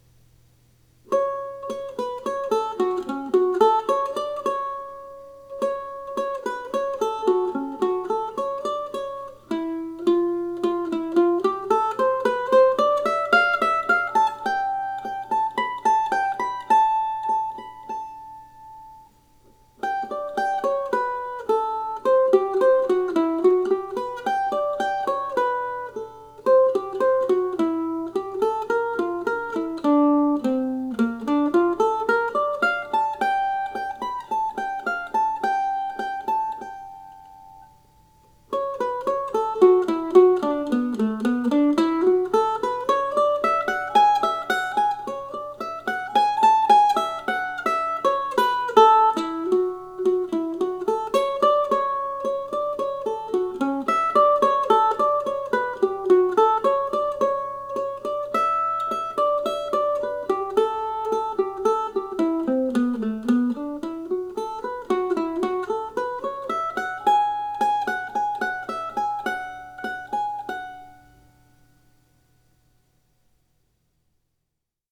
But I wrote another of my little Deer Tracks pieces yesterday and managed to record it this morning.
This is the view I enjoyed while writing the music yesterday and recording it today.